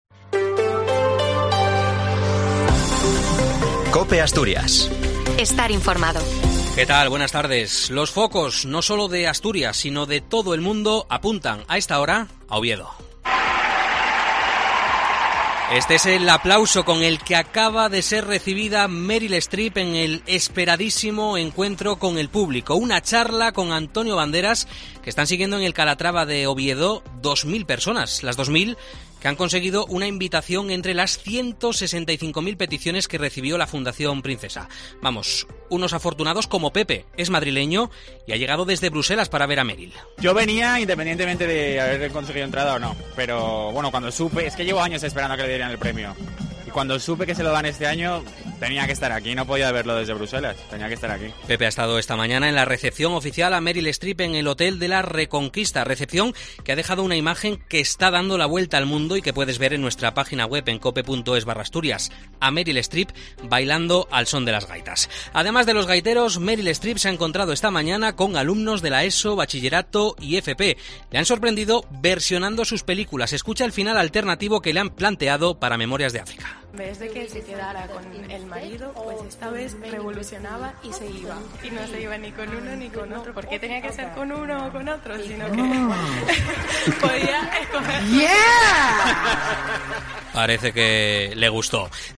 COPE cuenta cómo ha sido el inicio del acto entre Meryl Streep y Antonio Banderas en Asturias